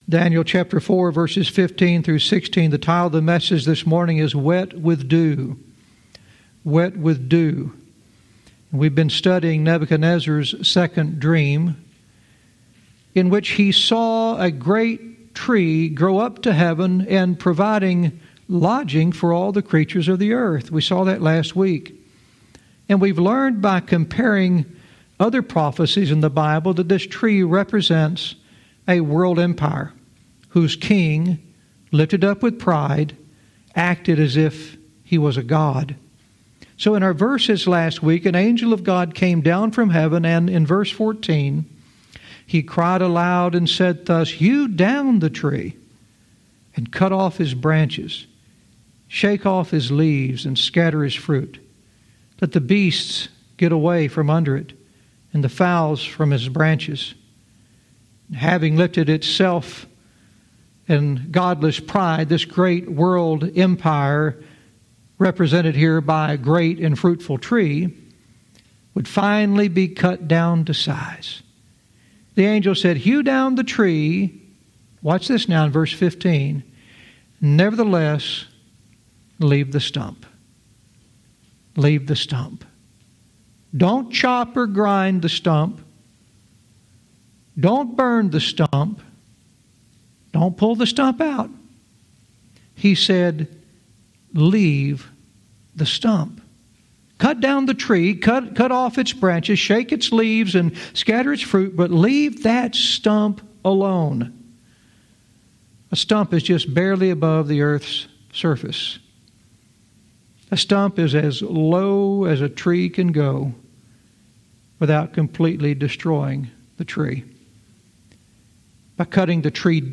Verse by verse teaching - Daniel 4:15-16 "Wet with Dew"